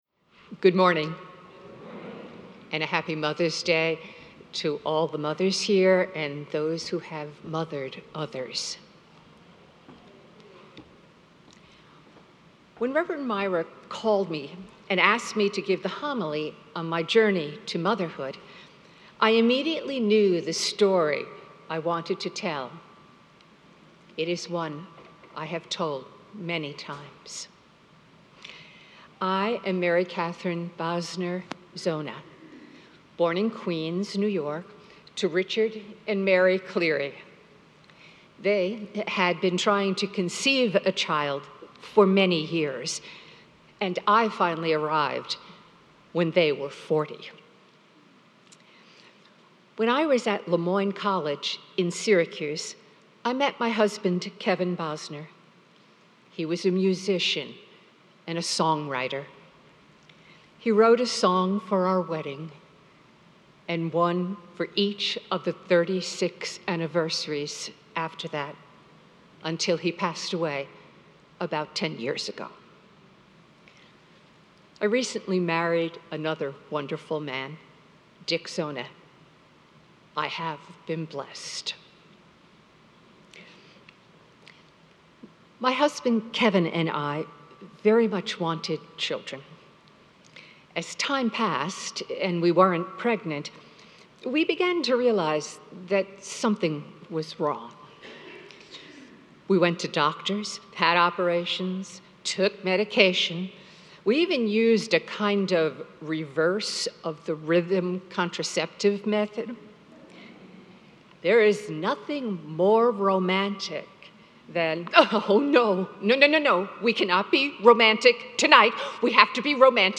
Mother's Day Liturgy 2022